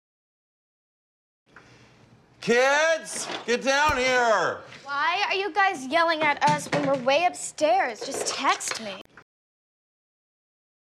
در جملات بالا، پدر بچه ها را که در طبقه بالا هستند را برای غذا صدا می کند که پایین بیایند. یکی از بچه ها اعتراض می کند که چرا وقتی داریم از پله ها می اییم پایین، شما فریاد می کشید.